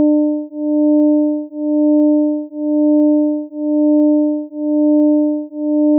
実部(右イヤホン)にはcos(2πt/2)
実部：cos(2πt/2)×(0.378*sin2π×300t+0.143*sin2π×600t+0.0166*sin2π×900t)